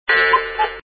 cuckoo.mp3